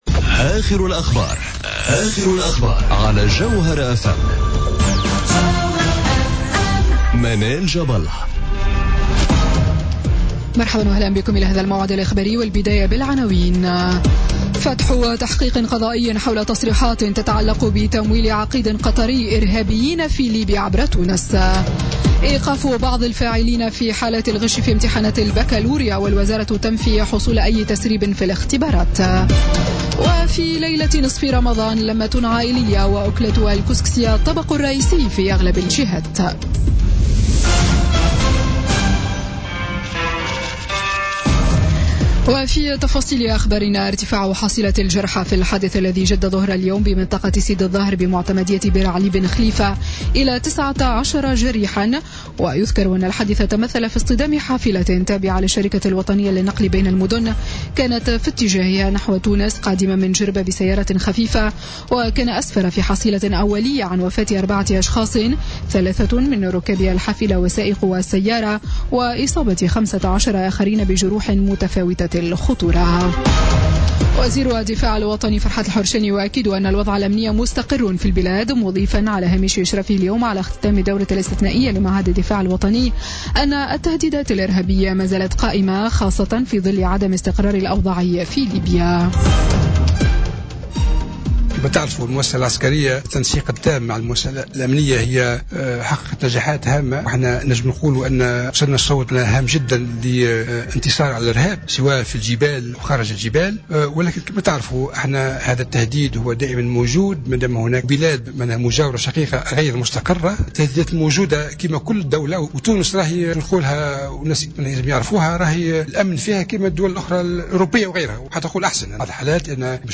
نشرة أخبار السادسة مساء ليوم الجمعة 9 جوان 2017